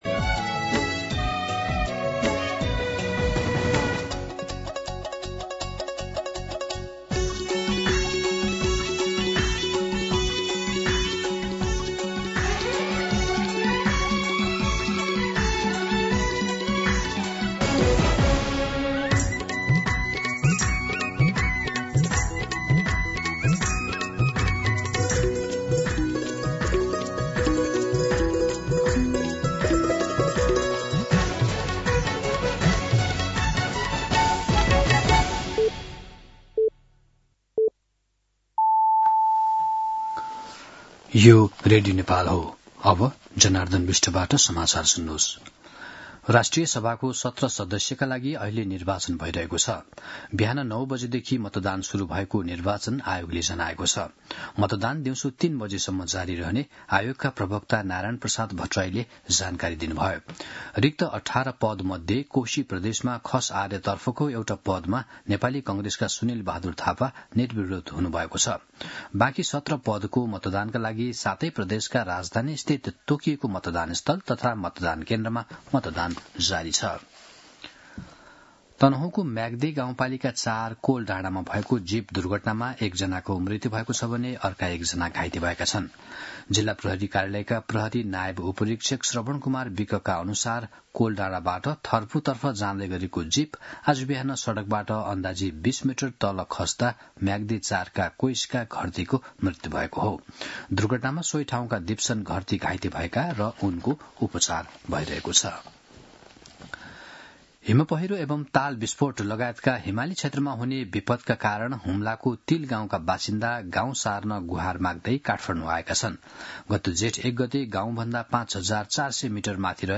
मध्यान्ह १२ बजेको नेपाली समाचार : ११ माघ , २०८२